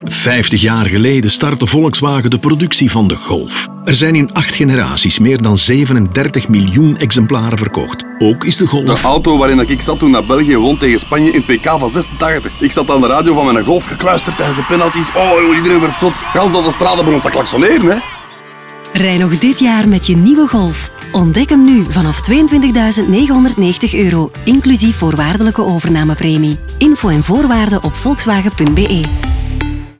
We zien de Golf als metgezel doorheen het leven van een moeder en haar dochter, en ontdekken uit het leven gegrepen anekdotes in de radiospots voor de nieuwe Golf.